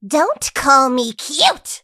willow_kill_vo_04.ogg